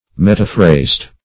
metaphrased \met"a*phrased\ (m[e^]t"[.a]*fr[=a]zd)
metaphrased.mp3